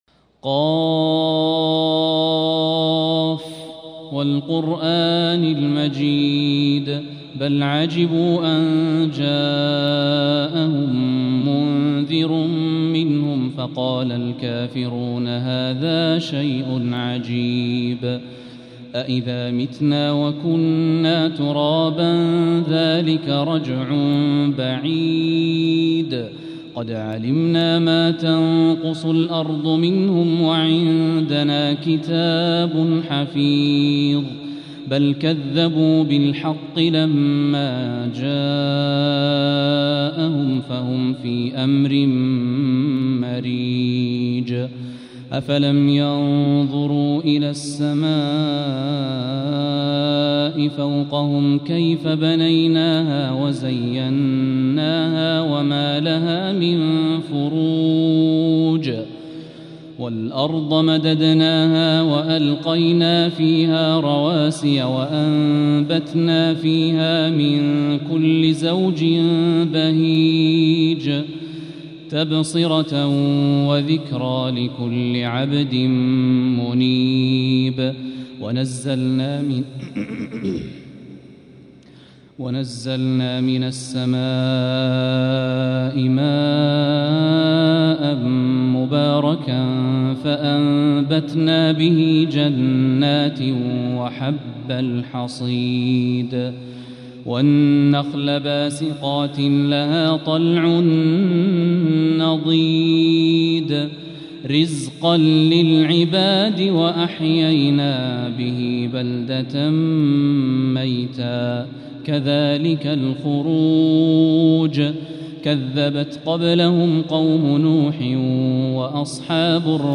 سورة ق | تهجد الحرم المكي عام 1445هـ